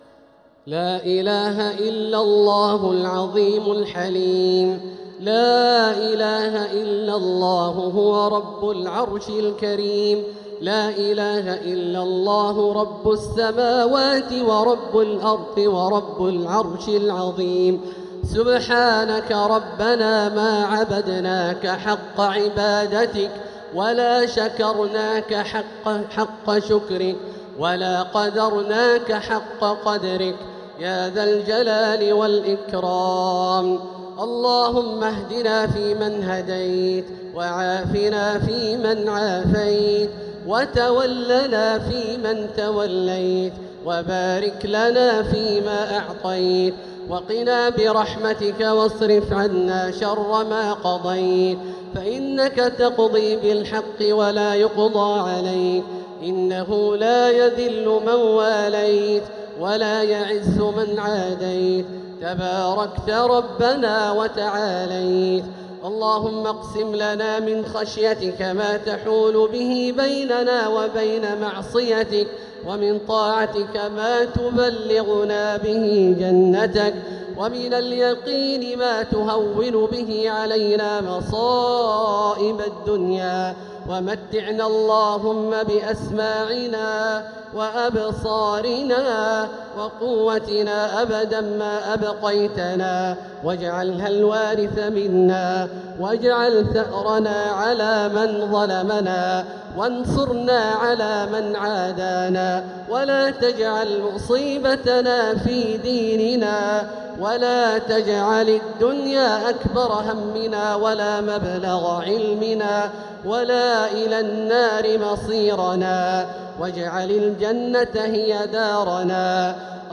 دعاء القنوت ليلة 17 رمضان 1447هـ > تراويح 1447هـ > التراويح - تلاوات عبدالله الجهني